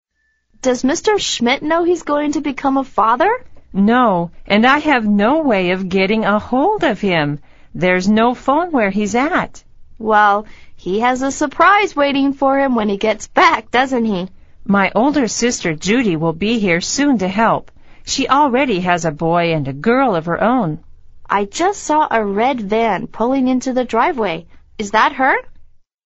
美语会话实录第113期(MP3+文本):Get a hold of